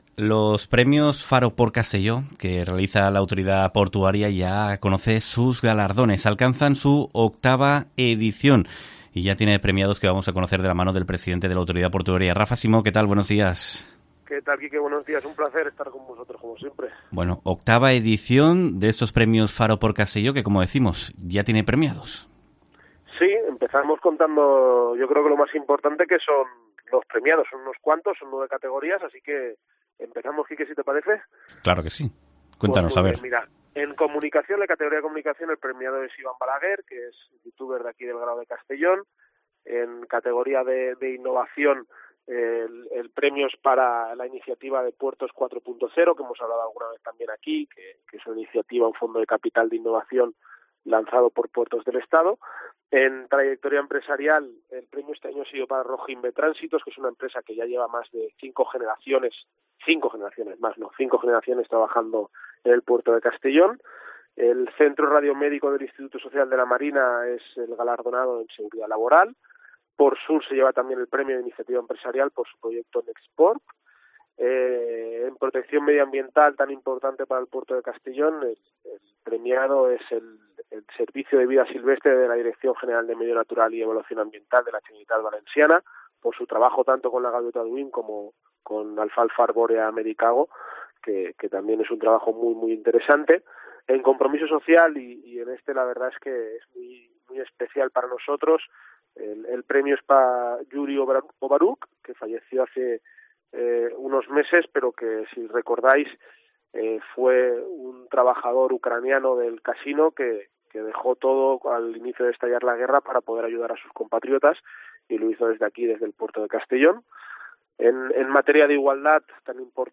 Entrevista
VIII Premios Faro PortCastelló de la Autoridad Portuaria de Castellón que presenta en COPE su presidente, Rafa Simó